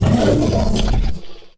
attack1.wav